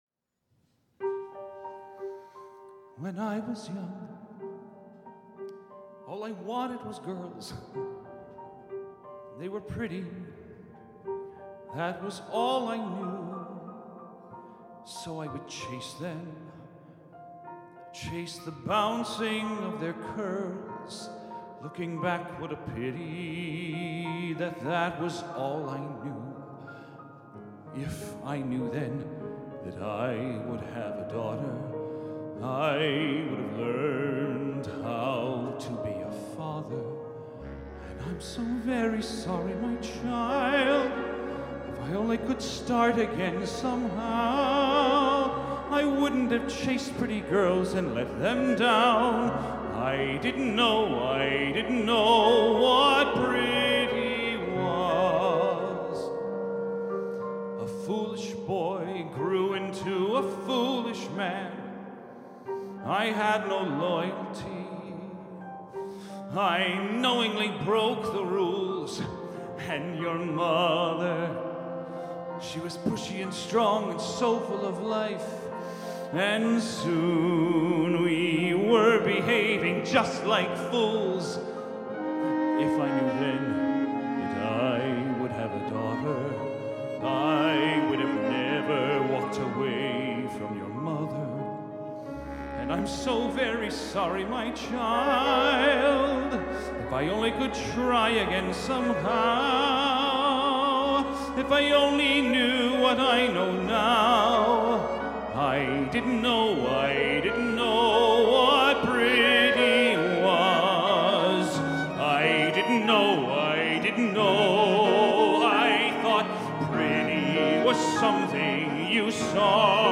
Live at Feinstein's/54 Below